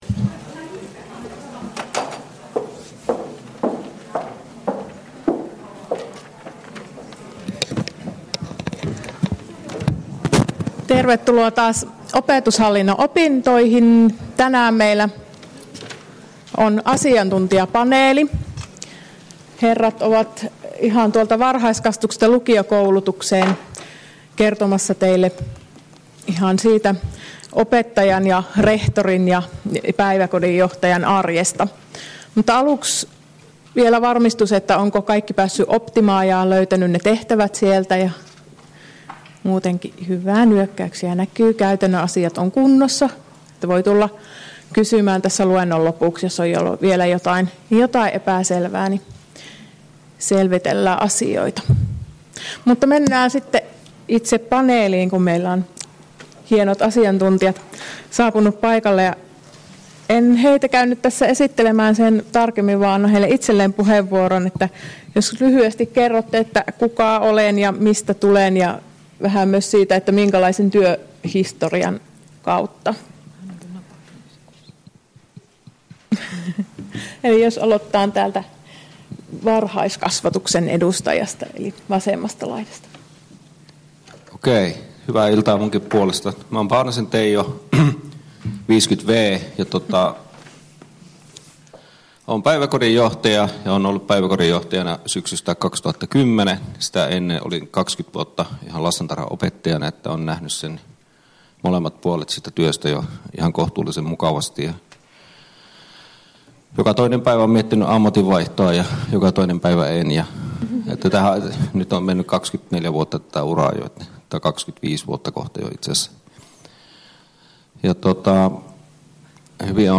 Luento 5.2.2015 — Moniviestin